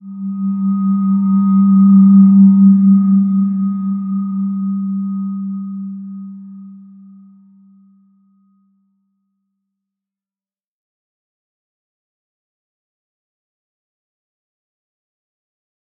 Slow-Distant-Chime-G3-mf.wav